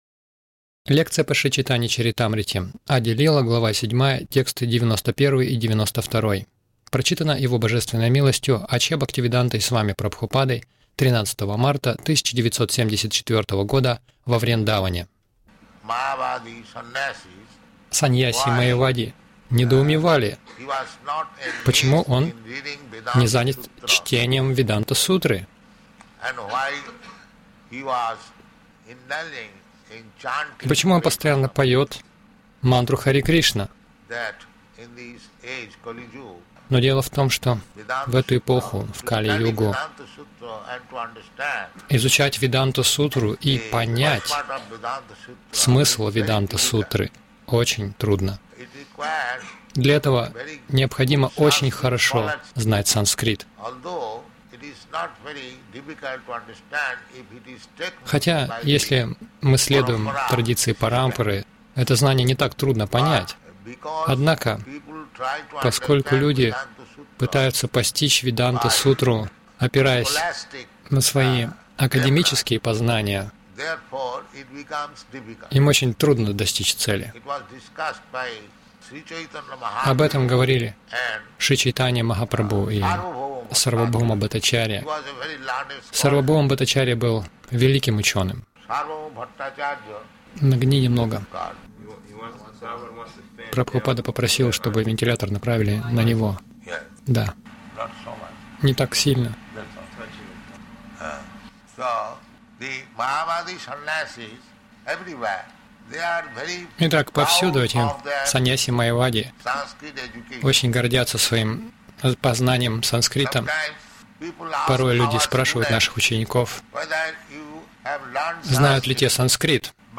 Милость Прабхупады Аудиолекции и книги 13.03.1974 Чайтанья Чаритамрита | Вриндаван ЧЧ Ади-лила 7.91-92 — Харе Кришна мантра вместо Веданта сутры Загрузка...